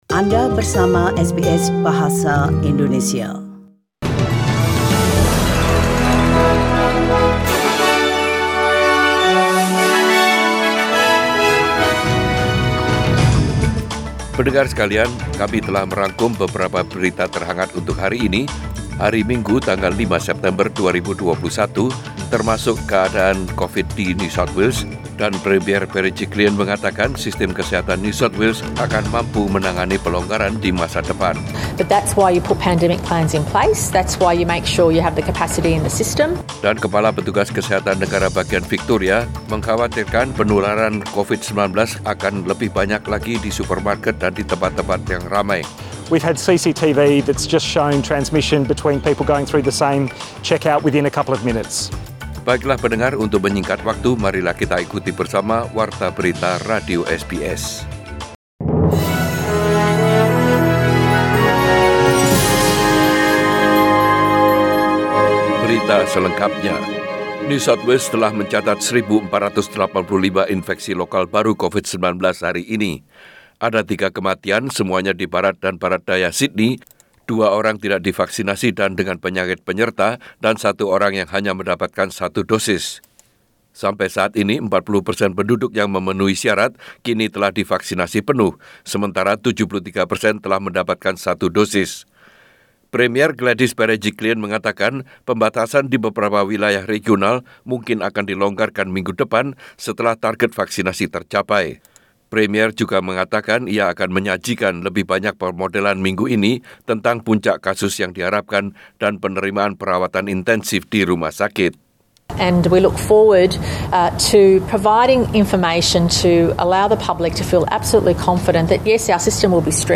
Warta Berita SBS Radio dalam Bahasa Indonesia Source: SBS